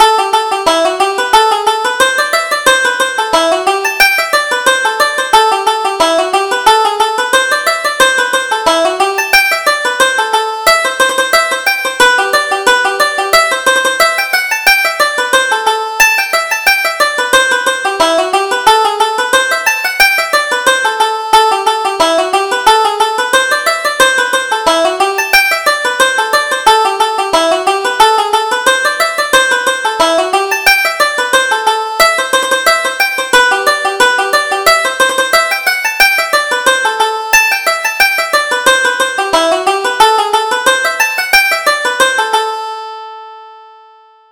Reel: Follow Me Down to Carlow - 1st Setting